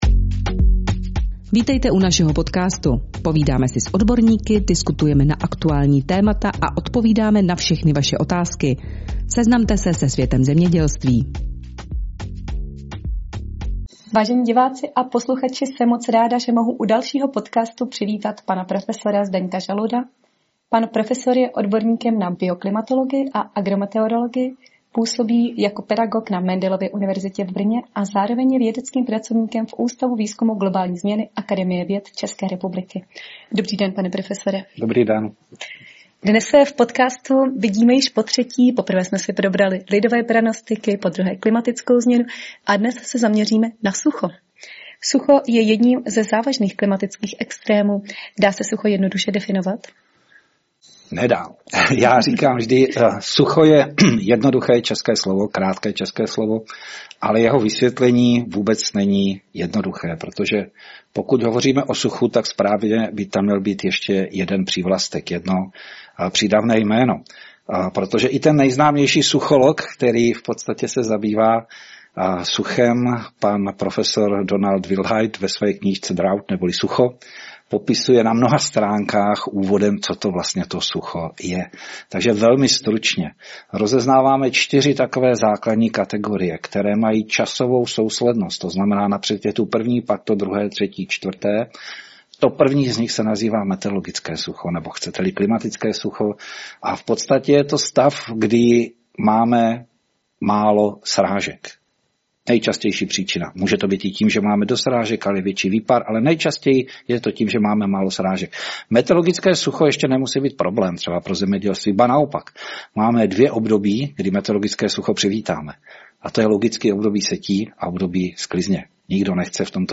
Odborná diskuse